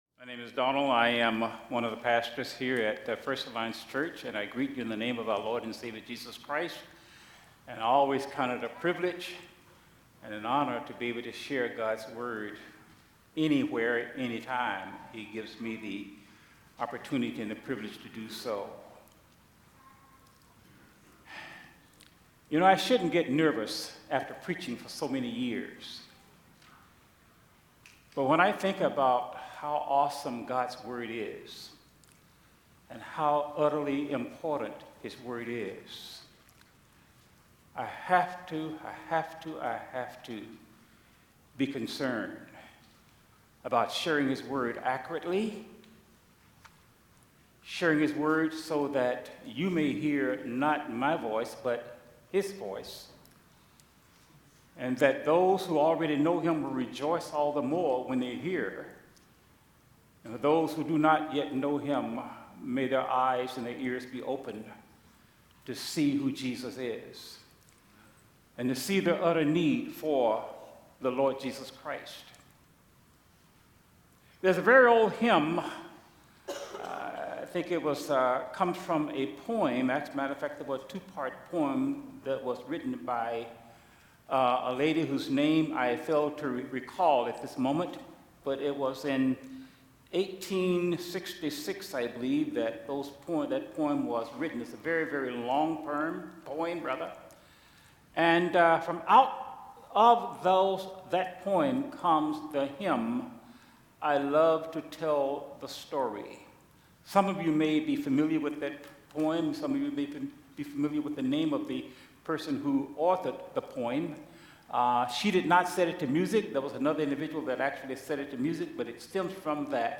Sermons | First Alliance Church Toledo